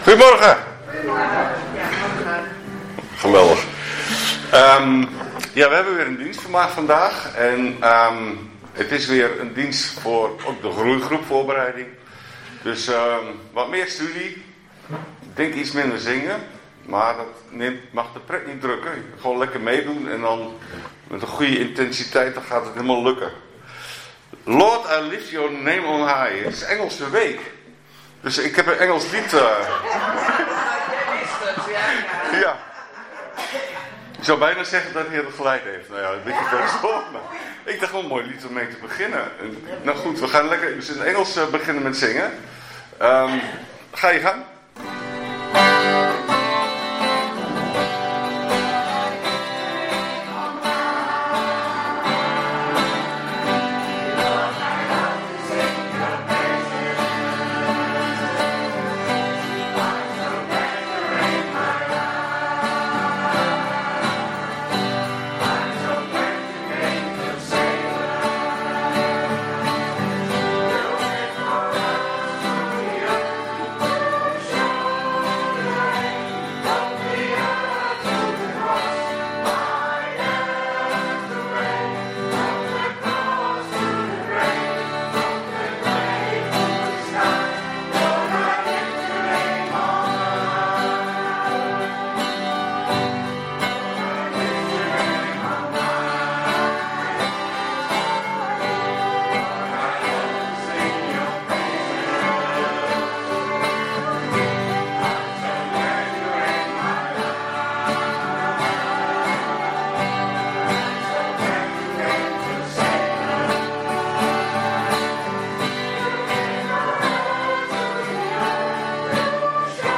15 maart 2026 dienst - Volle Evangelie Gemeente Enschede